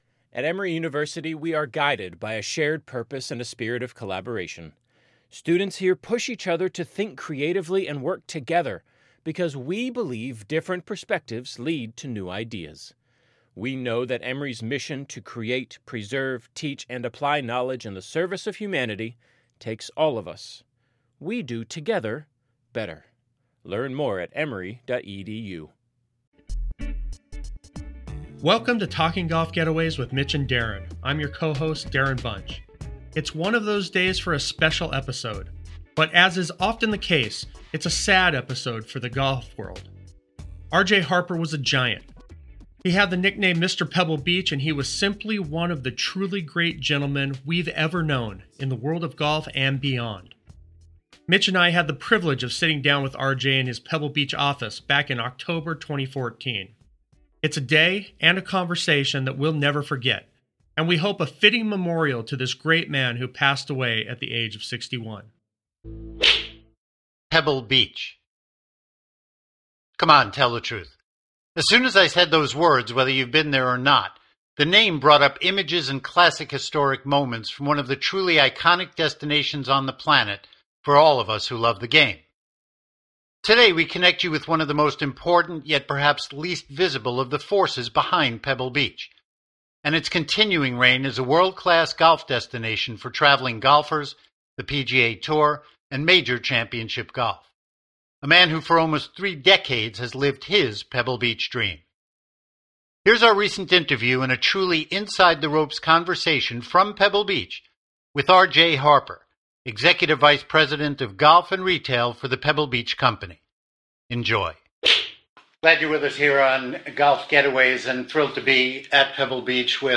Talking GolfGetaways: Your Golf Getaways Podcast